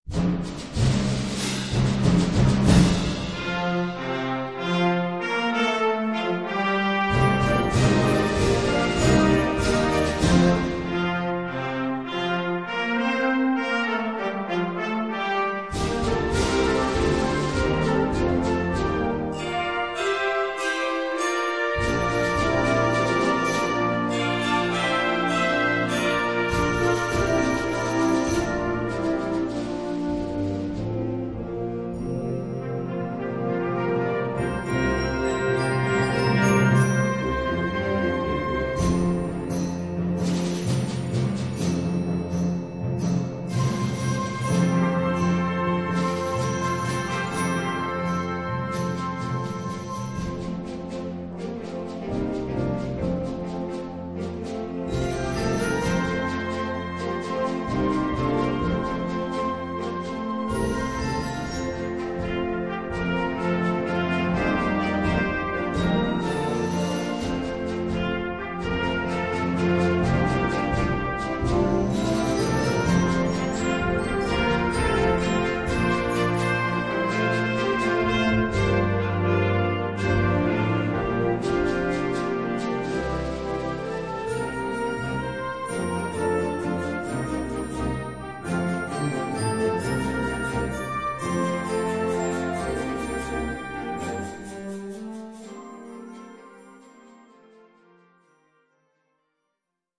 2:54 Minuten Besetzung: Blasorchester Zu hören auf